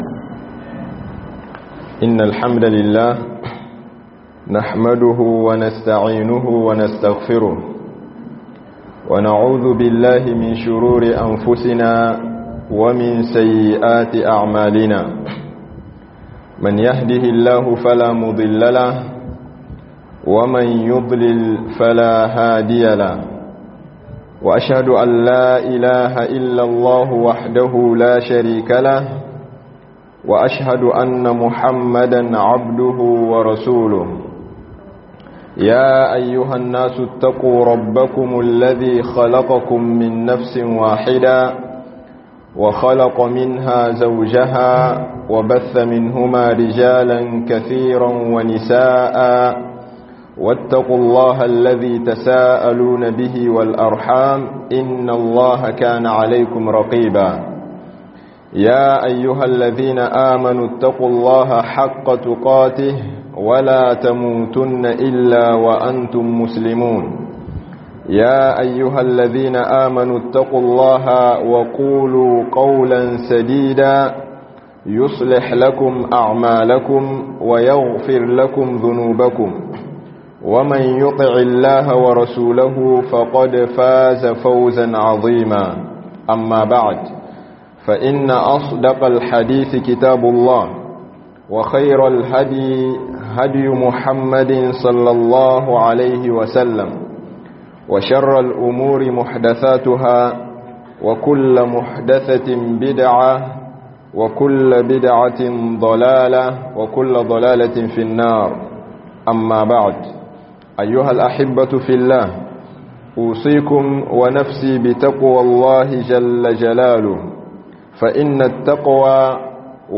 Halin Mumini a watan Shawwal - Hudubobi